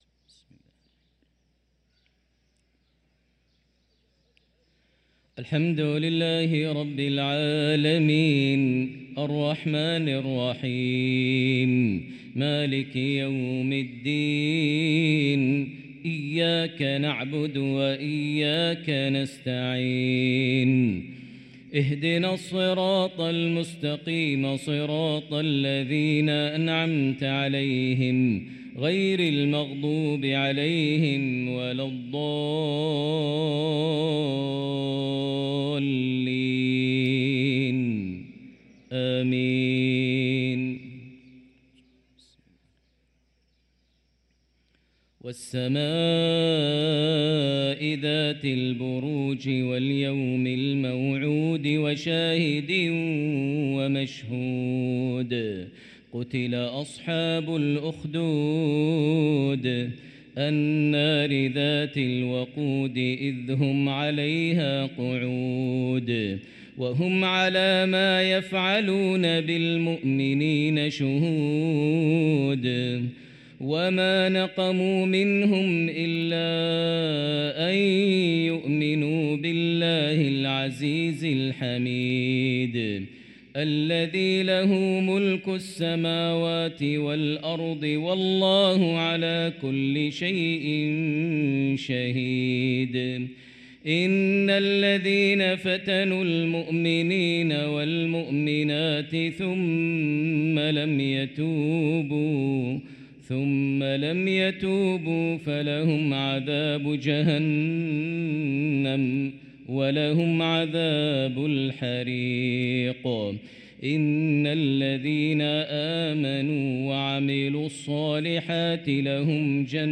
صلاة المغرب للقارئ ماهر المعيقلي 18 جمادي الأول 1445 هـ